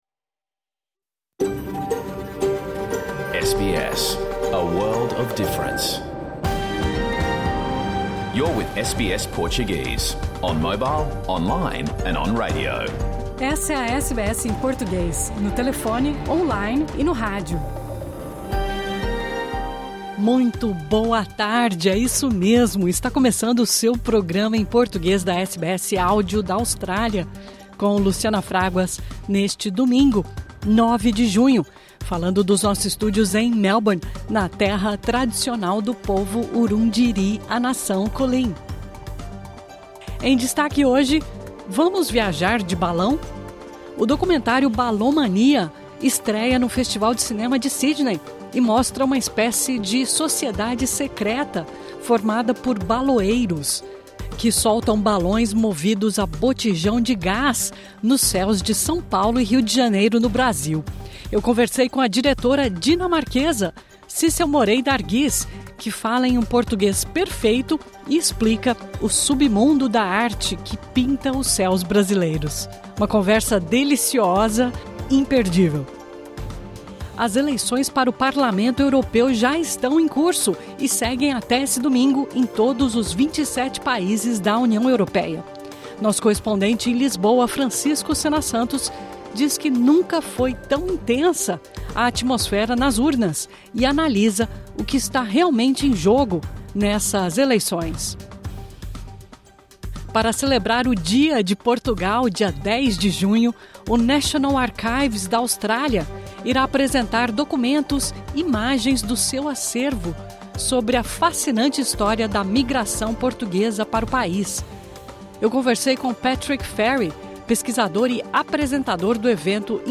Reportagens, artes e cultura, esporte e notícias, da Austrália e do mundo, no seu idioma. O programa que foi ao ar neste domingo pela SBS Áudio em toda a Austrália.